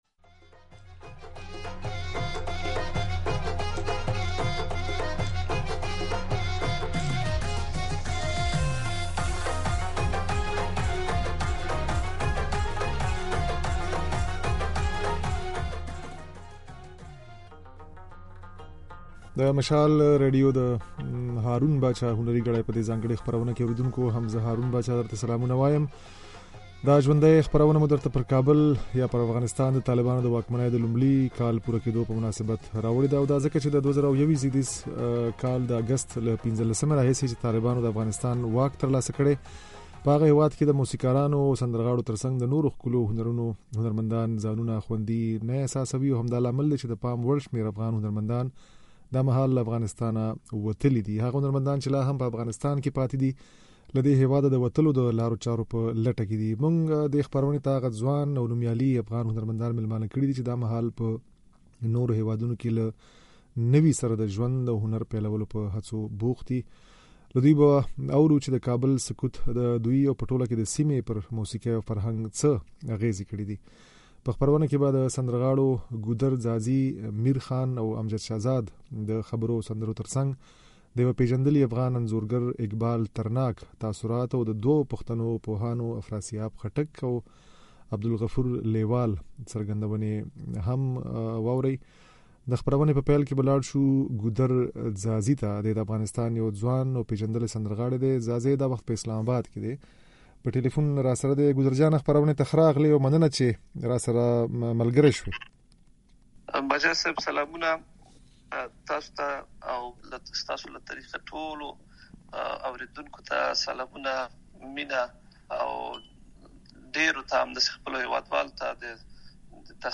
په خپرونه کې سندرې هم اورېدای شئ.
په خپرونه کې هغو افغان سندرغاړو چې دا وخت له افغانستان د باندې ژوند کولو ته اړ شوي، خپل احساسات او تاثرات شريک کړي. د چارو دوو شنونکو هم د پښتون افغان وطن د لرغوني موسيقۍ او فرهنګ پر ارزښت او ورته د طالبانو له لاسه پر پېښو خطرونو رڼا واچوله.